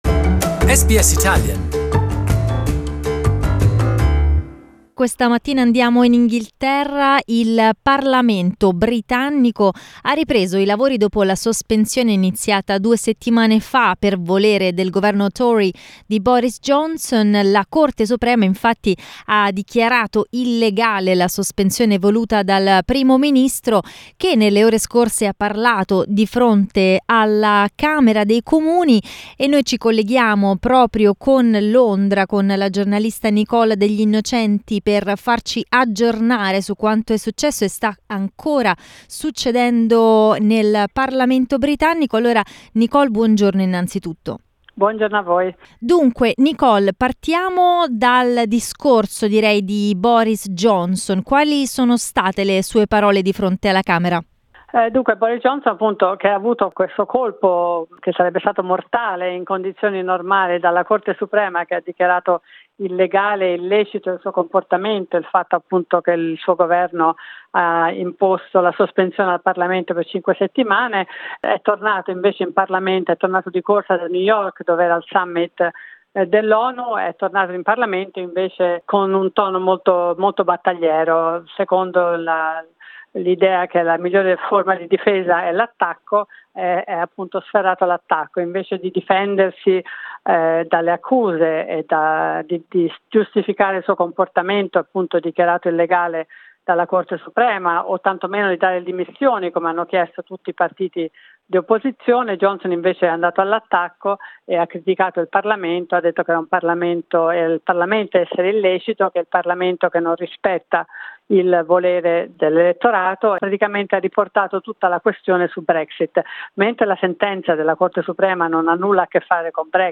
SBS Italian